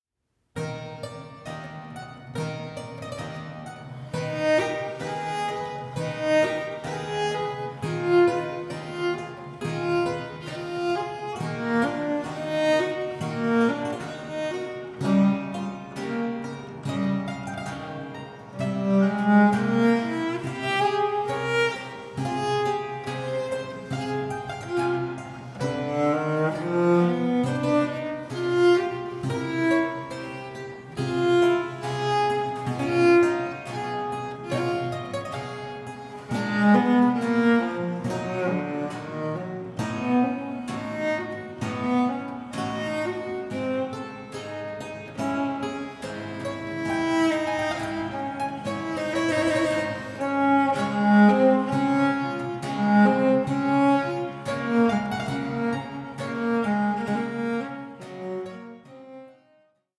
five-string cello piccolo
clavichord